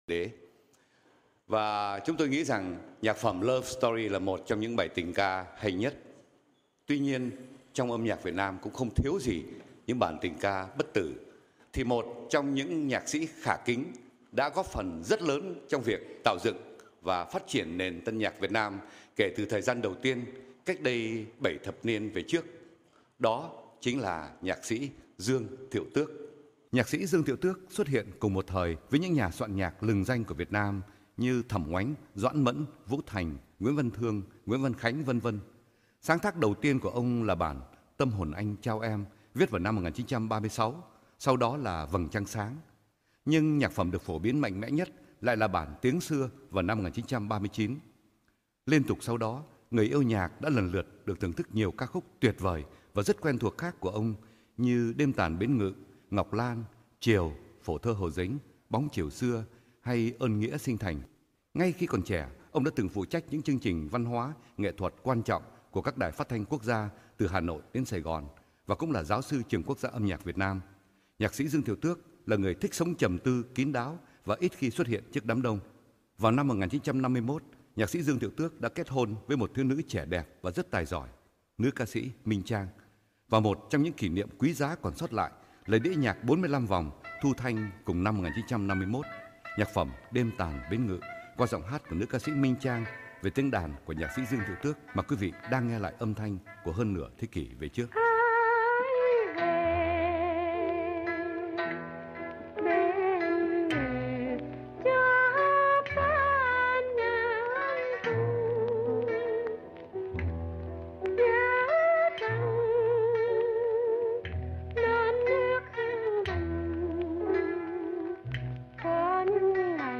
Nam Lộc phỏng vấn Danh Ca Minh Trang